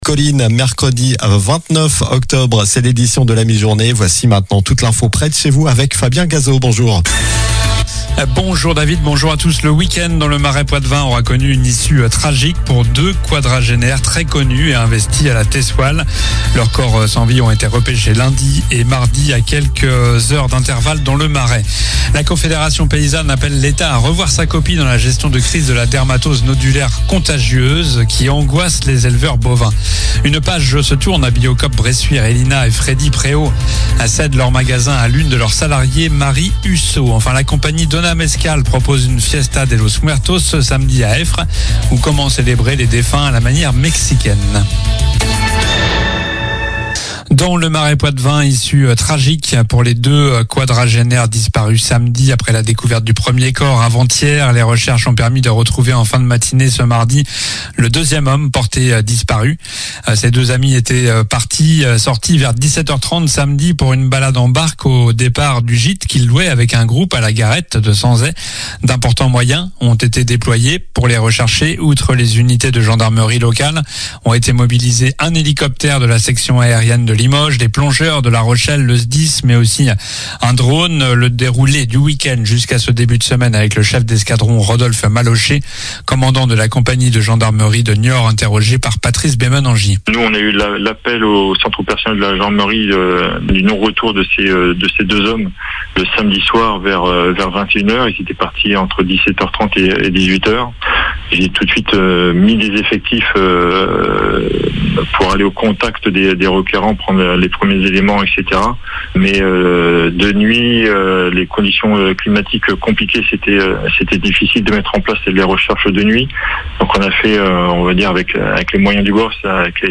Journal du mercredi 29 octobre (midi)